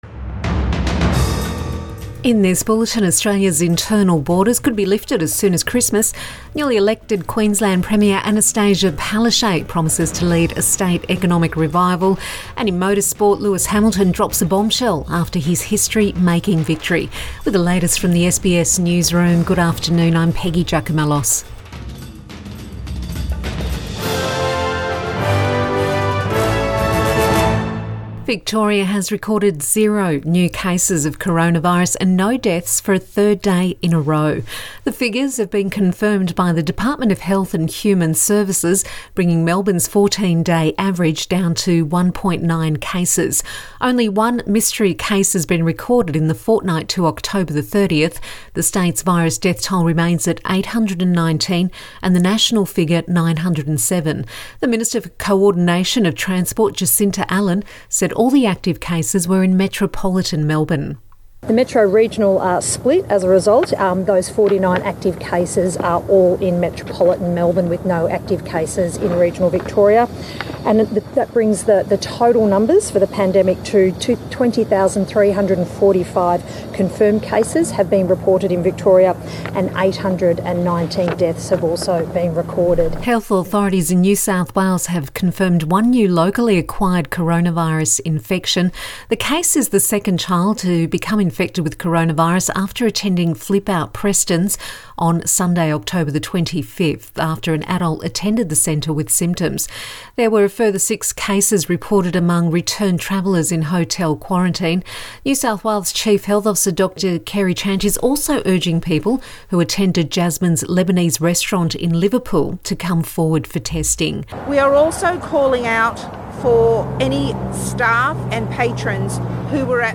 Midday bulletin 2 November 2020